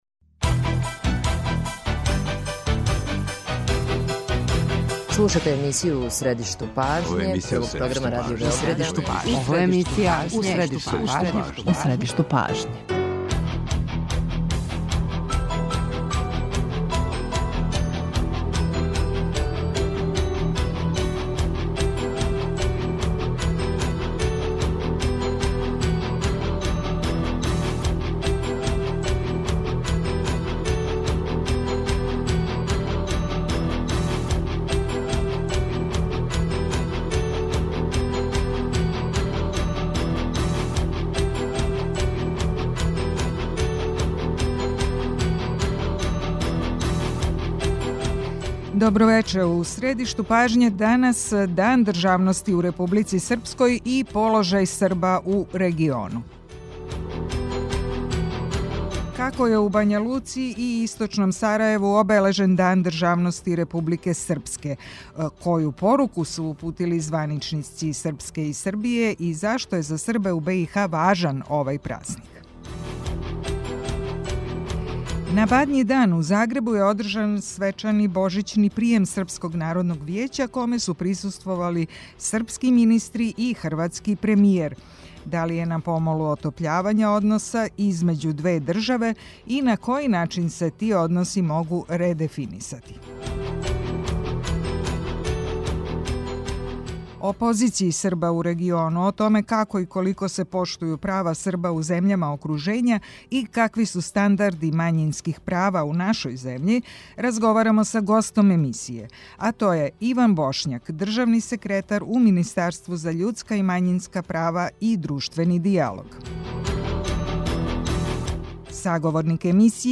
Свакога радног дана емисија "У средишту пажње" доноси интервју са нашим најбољим аналитичарима и коментаторима, политичарима и експертима, друштвеним иноваторима и другим познатим личностима, или личностима које ће убрзо постати познате.
О позицији Срба у региону, о томе како и колико се поштују права Срба у земљама окружења и какви су стандарди мањинских права у нашој земљи разговарамо са гостом емисје, а то је Иван Бошњак, државни секретар у Министарству за људска и мањинска права и друштвени дијалог.